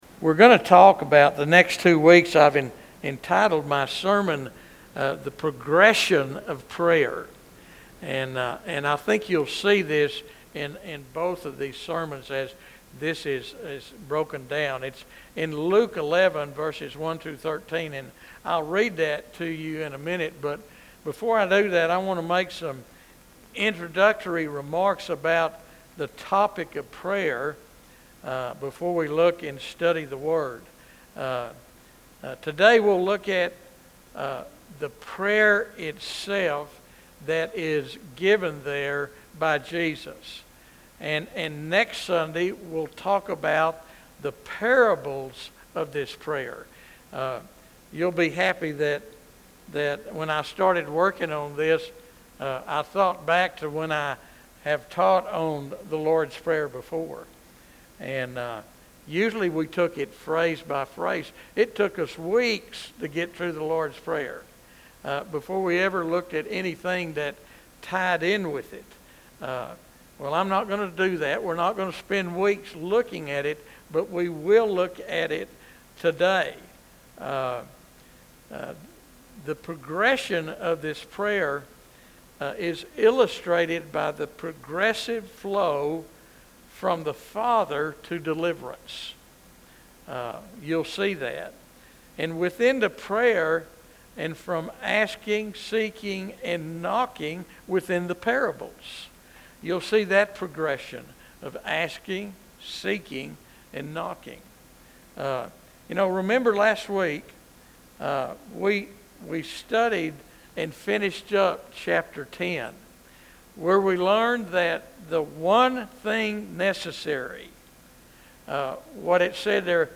Highlights Include: • Moving testimony of God's healing power in trauma recovery • Practical insights on making time for daily spiritual feeding • Personal stories that illustrate each element of the Lord's Prayer • Guided prayer time to apply these truths to your own life